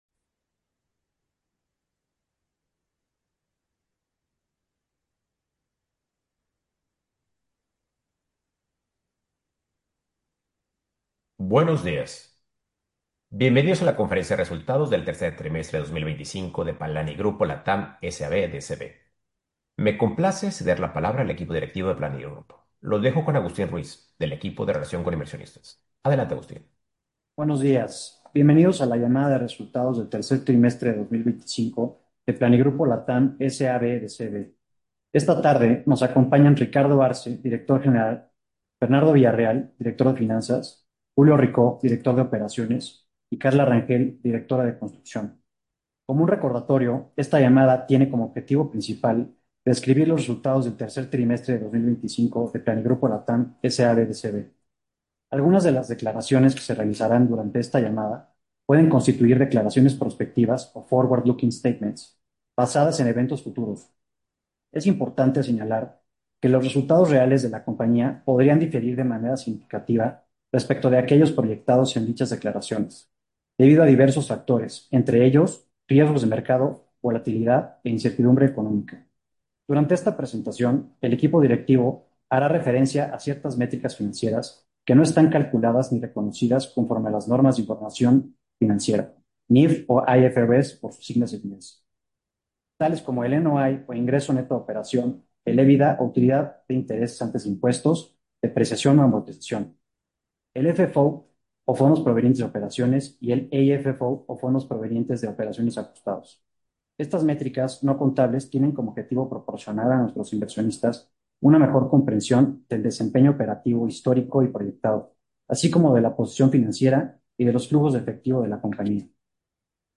Resultados del Tercer Trimestre 2025 Audio de la Llamada de Resultados del 3er Trimestre 2025